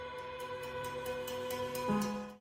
A Ballpoint Pen Dancing on Mandolin Strings
dinkdinkdink.mp3